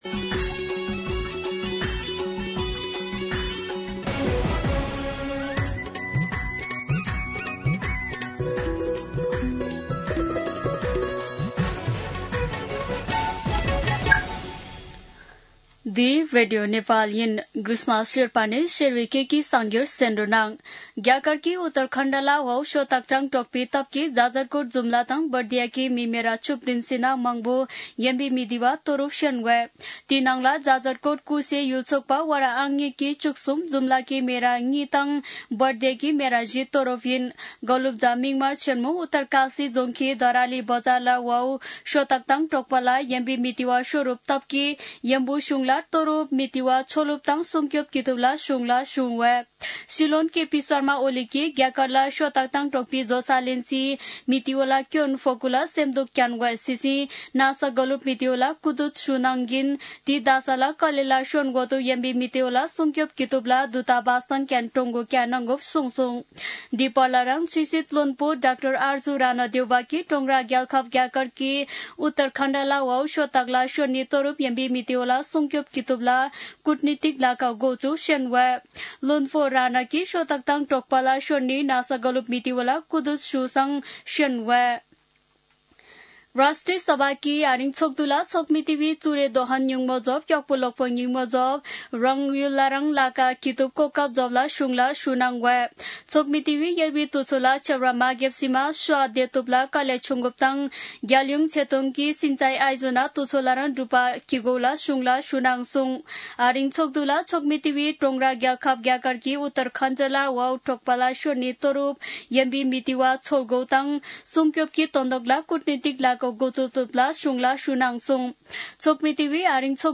An online outlet of Nepal's national radio broadcaster
शेर्पा भाषाको समाचार : २३ साउन , २०८२
Sherpa-News-.mp3